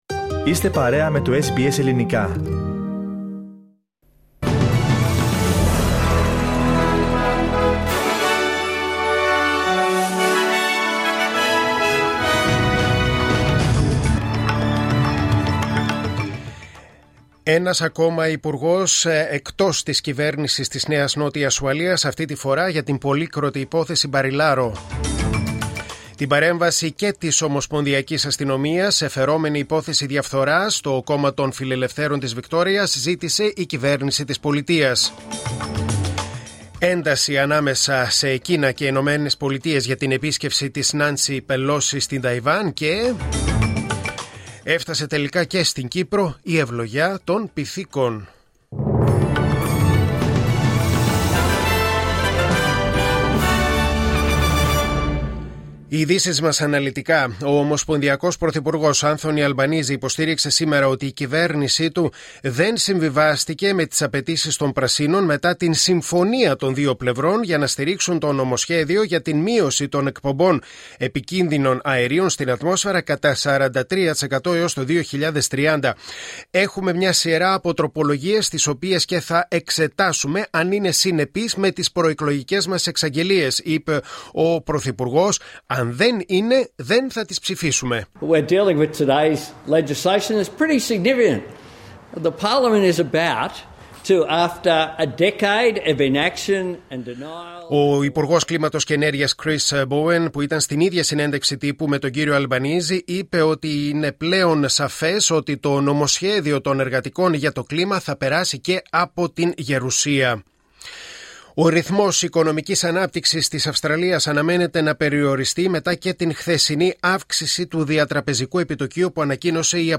Δελτίο Ειδήσεων: Τετάρτη 3.8.2022